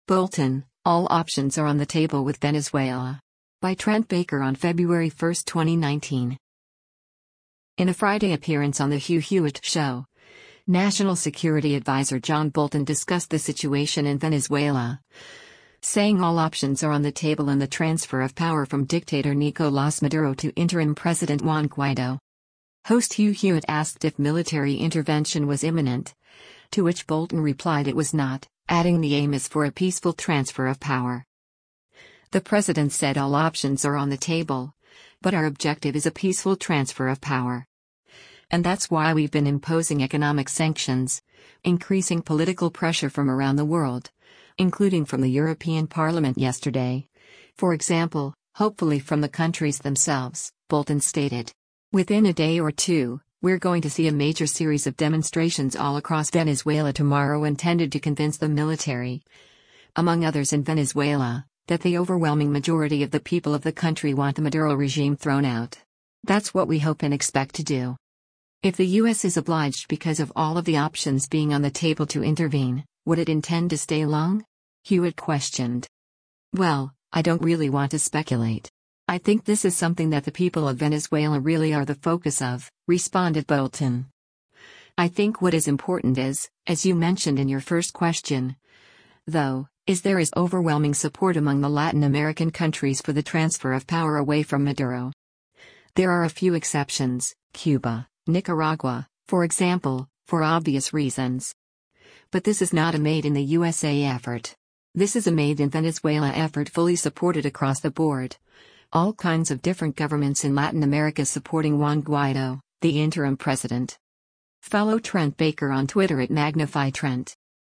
In a Friday appearance on “The Hugh Hewitt Show,” National Security advisor John Bolton discussed the situation in Venezuela, saying “all options are on the table” in the transfer of power from dictator Nicolás Maduro to interim President Juan Guaidó.
Host Hugh Hewitt asked if military intervention was “imminent,” to which Bolton replied it was not, adding the aim is for a “peaceful transfer of power.”